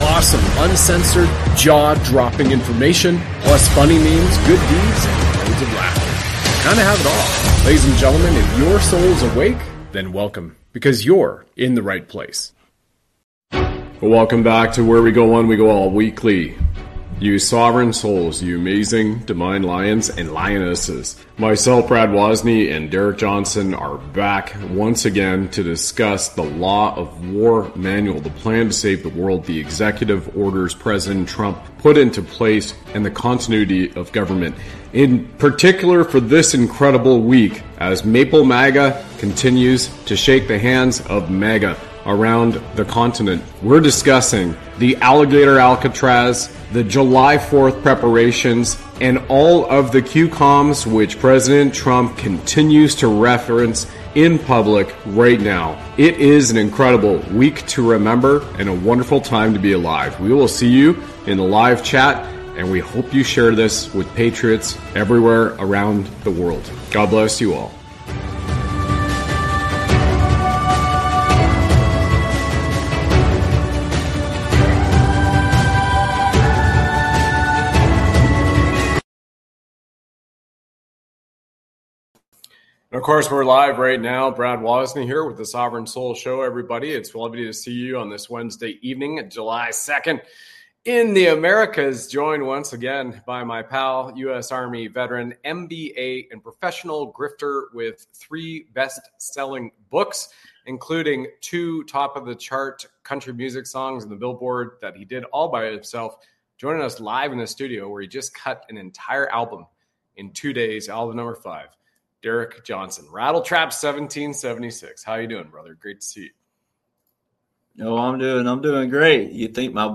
With humor and personal stories, they explore everything from military training to the influence of tech leaders. This lively conversation aims to entertain and inspire, encouraging unity and understanding in today's world.